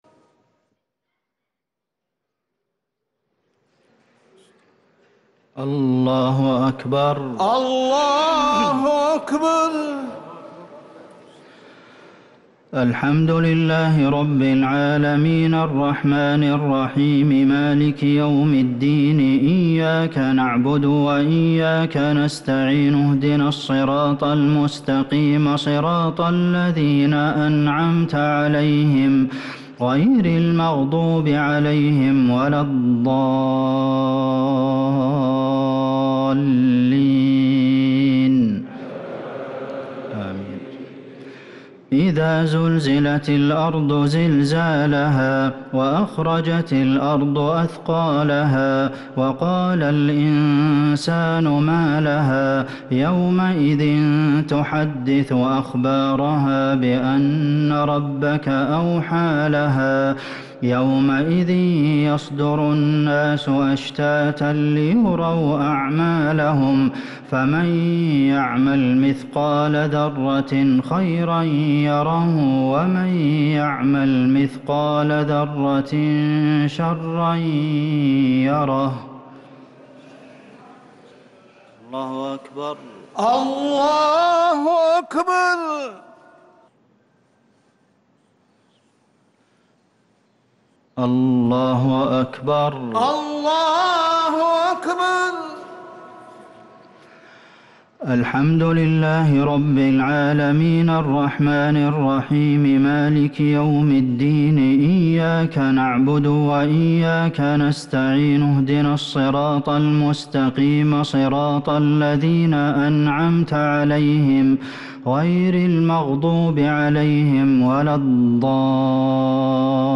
الشفع و الوتر ليلة 9 رمضان 1444هـ | Witr 9 st night Ramadan 1444H > تراويح الحرم النبوي عام 1444 🕌 > التراويح - تلاوات الحرمين